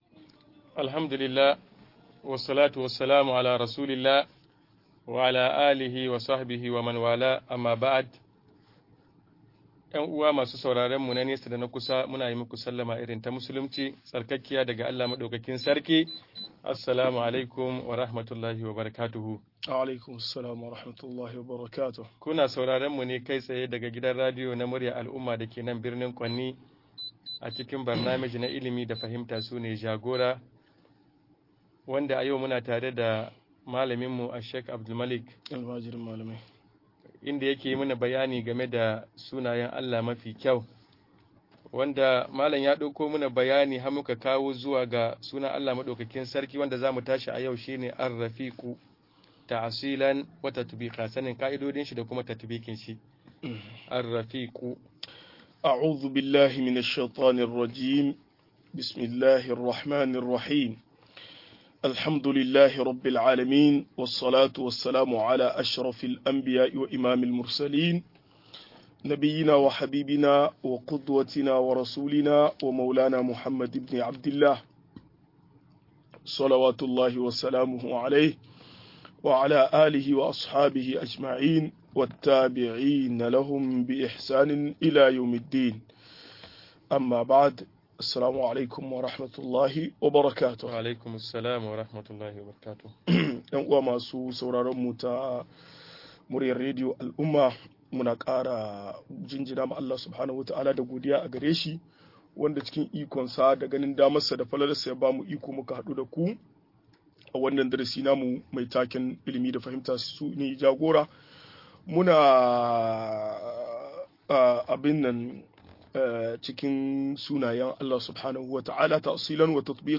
Sunayen Allah da siffofin sa-19 - MUHADARA